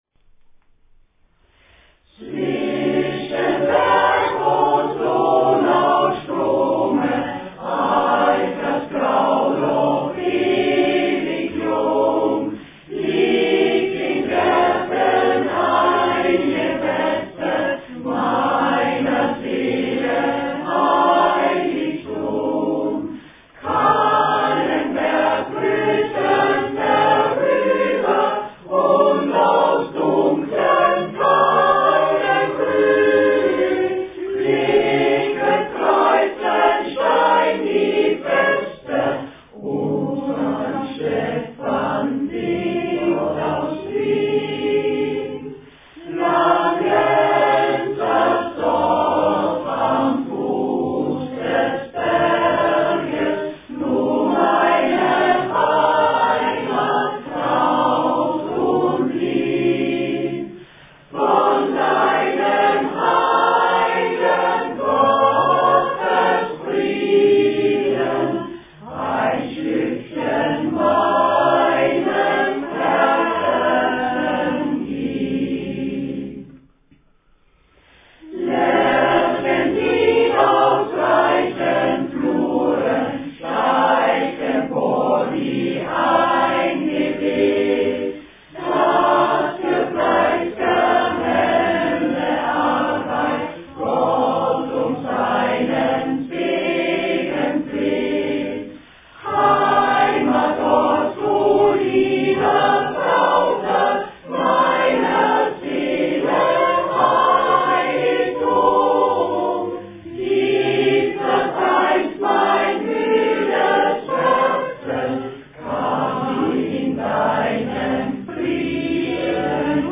Heimatlieder